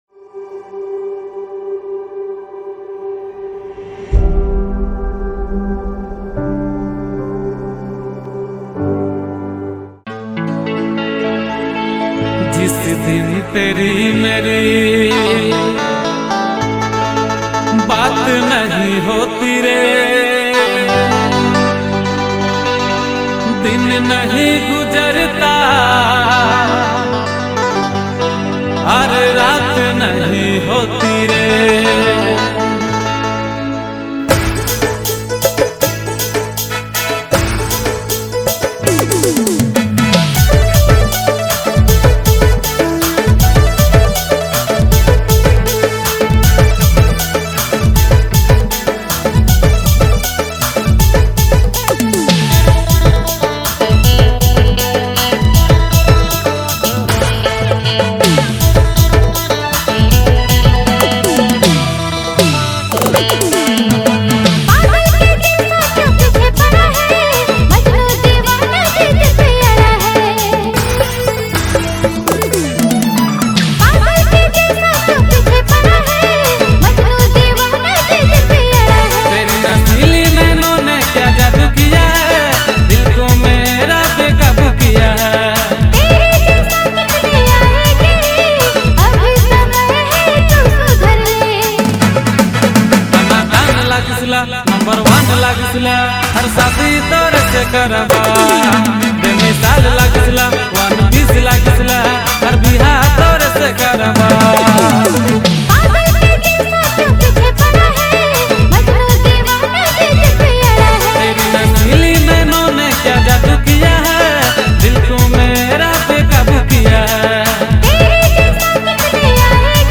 fresh and energetic Nagpuri song
vocals